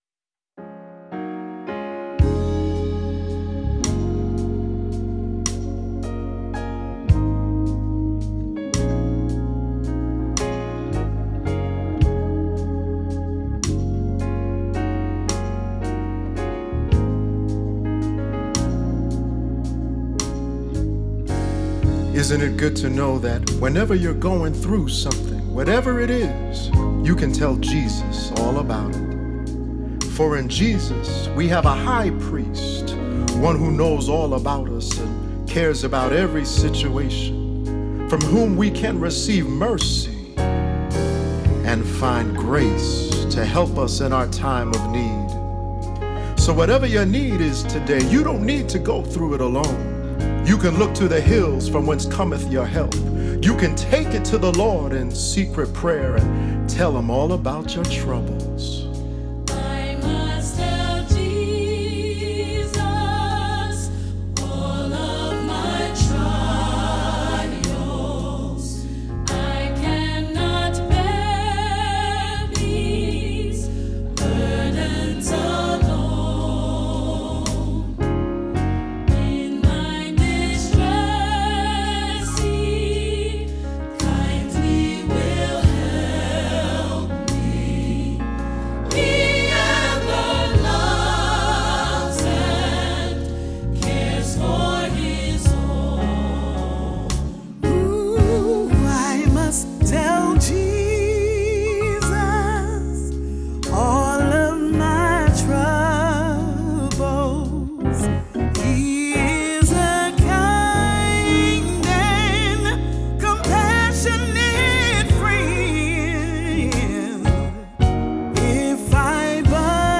Voicing: "SAT","SATB","Solo"